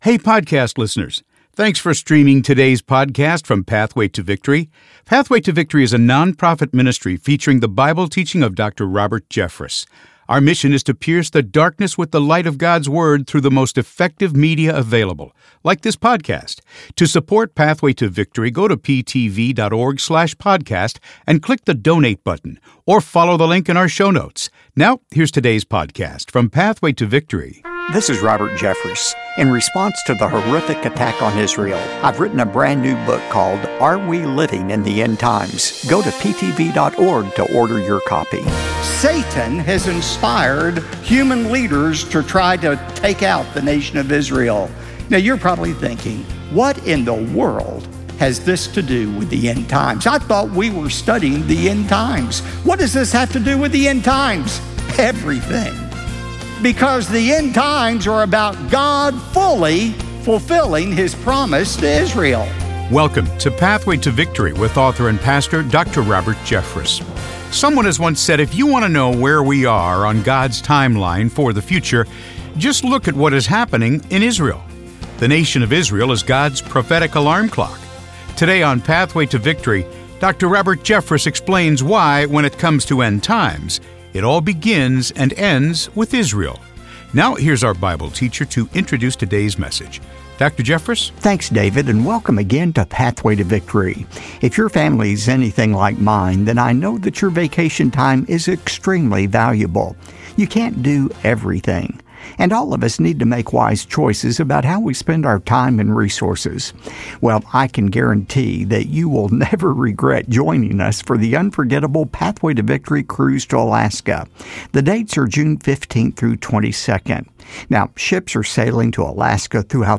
Israel End Times God's Promise Dr. Robert Jeffress Prophecy Bible Teaching discussion